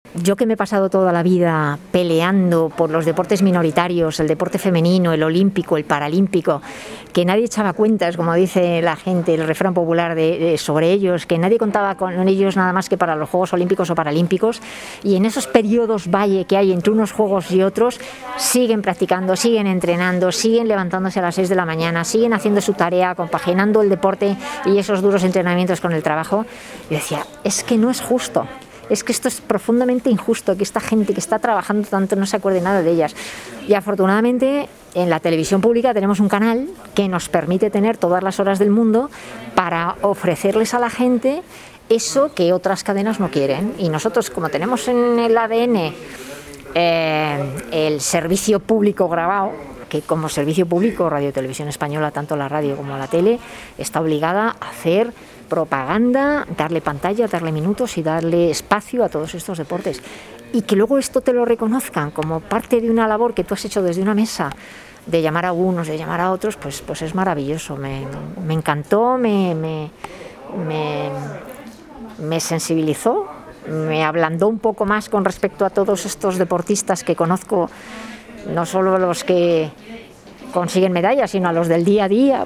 El reconocimiento a la fuerza y la solidaridad de la sociedad a través de la concesión de los Premios Solidarios ONCE 2021Abre Web externa en ventana nueva llegó, el pasado 15 de diciembre, a la Comunidad de Madrid, con la celebración de su ceremonia de entrega de galardones, que tuvo lugar en el Complejo Deportivo y Cultura de la ONCE, sito en el Paseo de La Habana, nº 208, de Madrid.